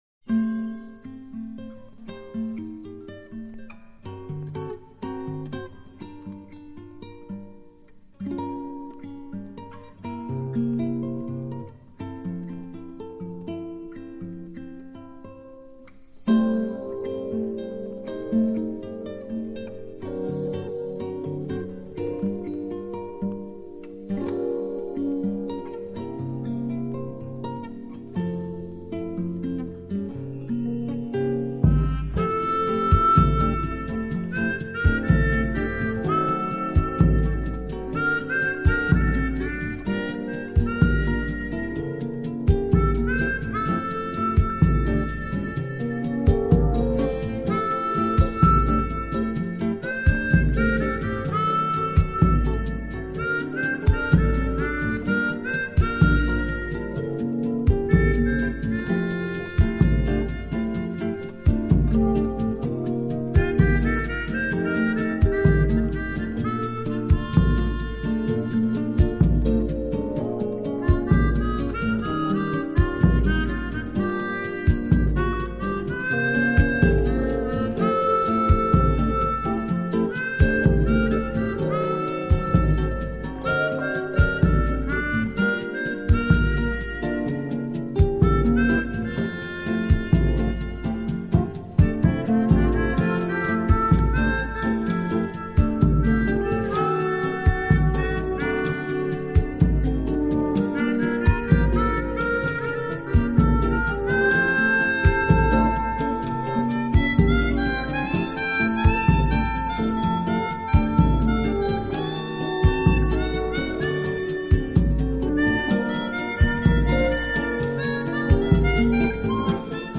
【Jazz & Fusion】